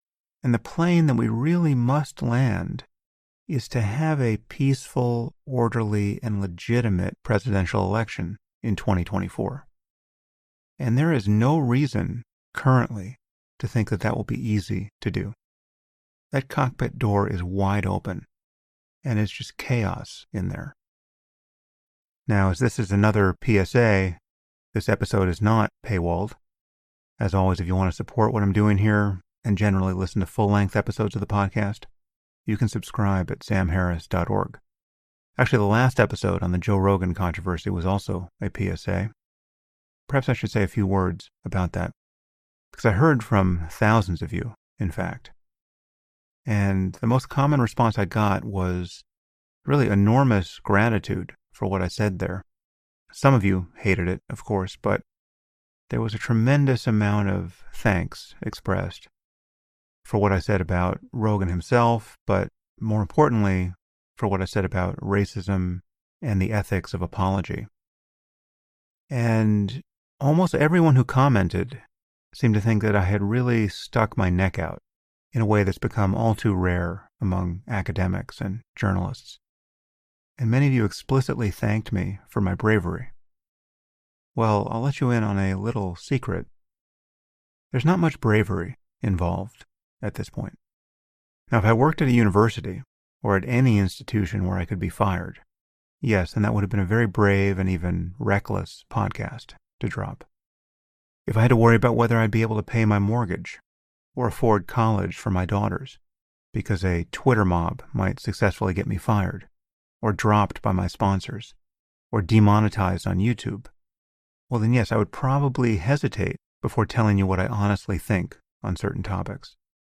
In this episode of the podcast, Sam Harris speaks with Anne Applebaum, David Frum, Barton Gellman, and George Packer.